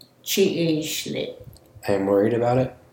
Worry /chi’i…lit/